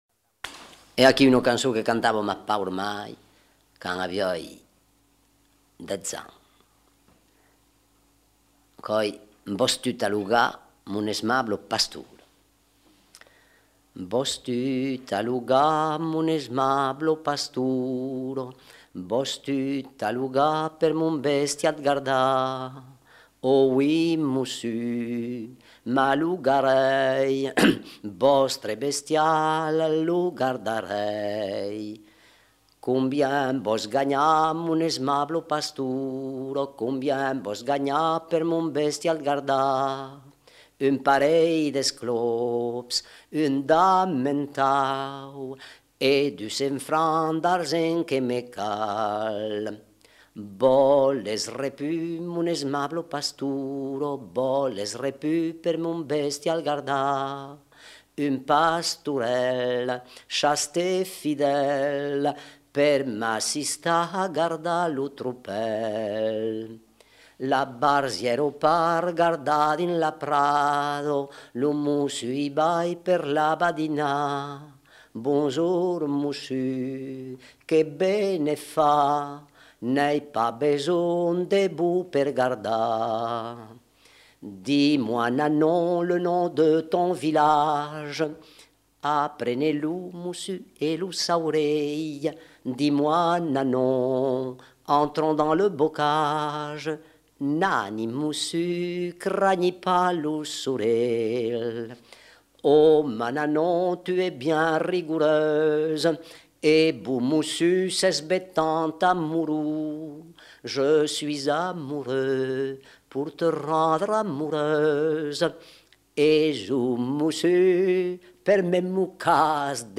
Lieu : Lauzun
Genre : chant
Effectif : 1
Type de voix : voix d'homme
Production du son : chanté